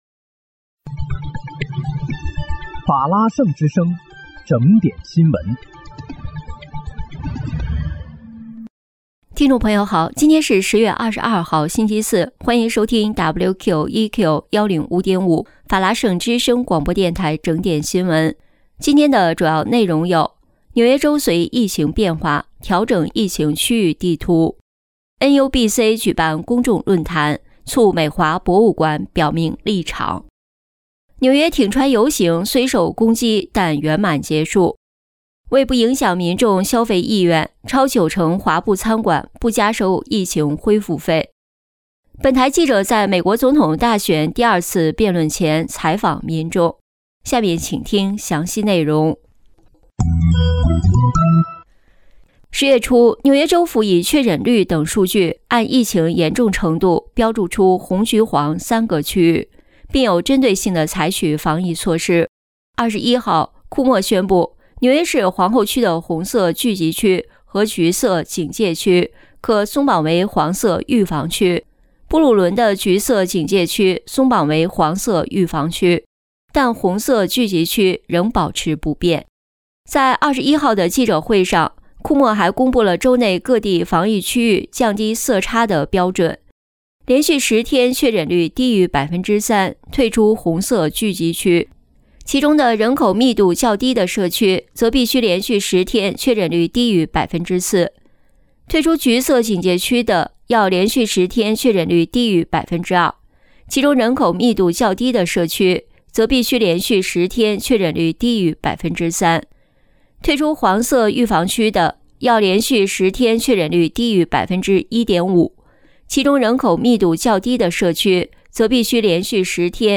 10月22日（星期四）纽约整点新闻